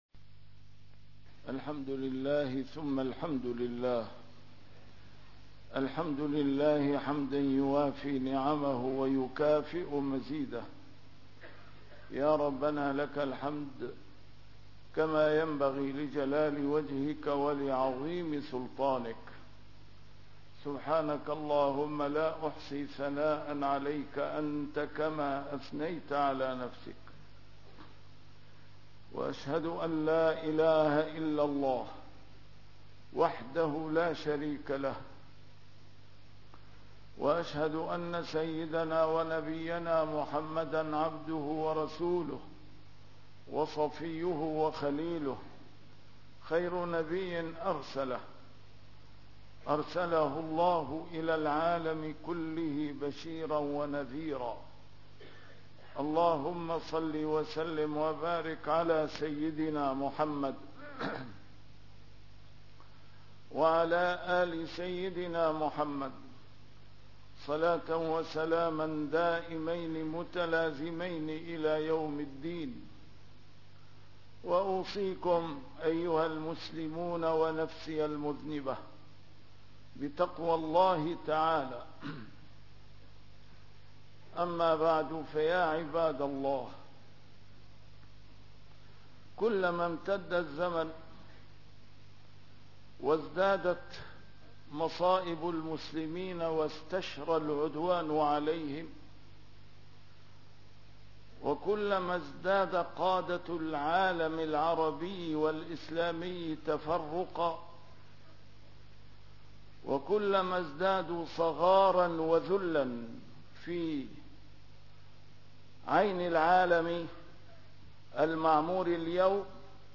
A MARTYR SCHOLAR: IMAM MUHAMMAD SAEED RAMADAN AL-BOUTI - الخطب - كارثة العالم الإسلامي بانهيار الخلافة الإسلامية